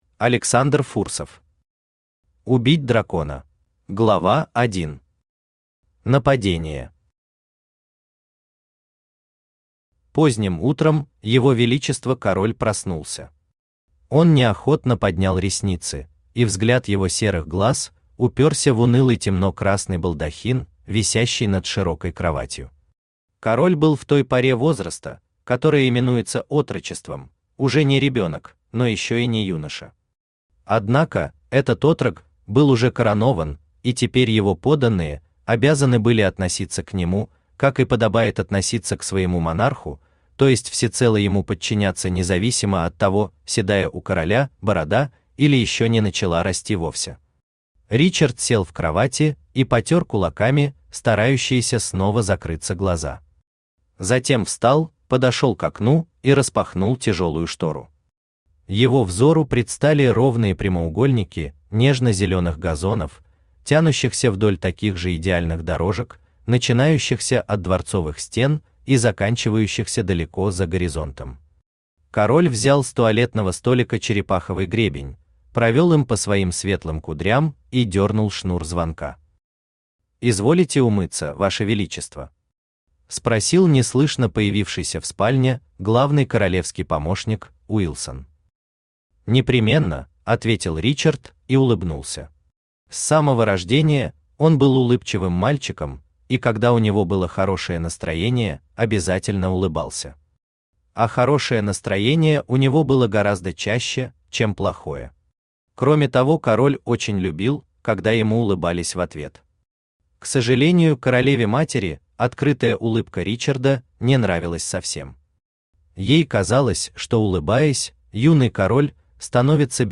Аудиокнига Убить дракона | Библиотека аудиокниг
Aудиокнига Убить дракона Автор Александр Иванович Фурсов Читает аудиокнигу Авточтец ЛитРес.